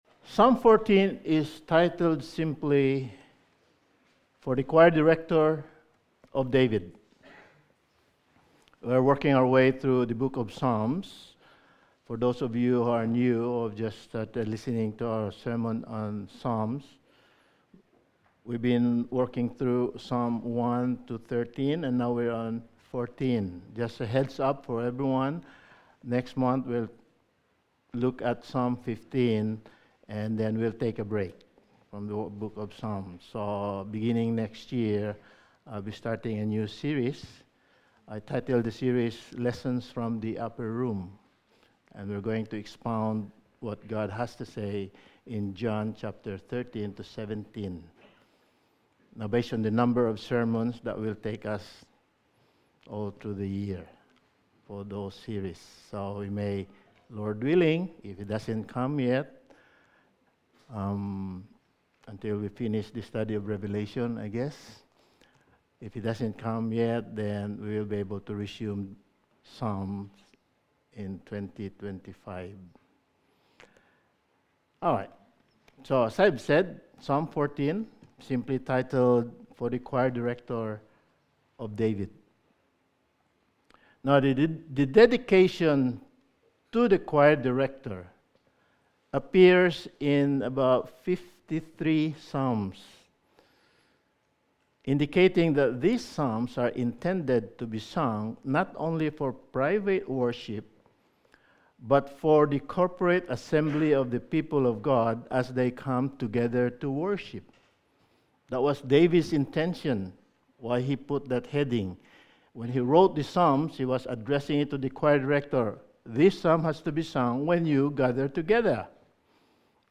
Sermon
Psalm 14:1-7 Service Type: Sunday Morning Sermon 16 « Studies in the Book of Ecclesiastes Sermon 22